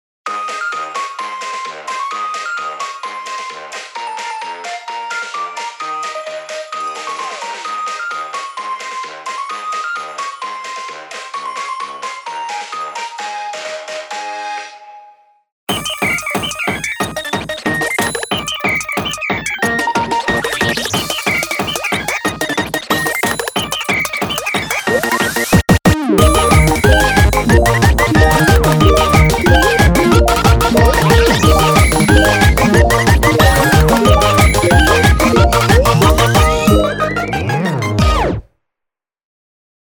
TVCM
INSTRUMENTAL ELECTRO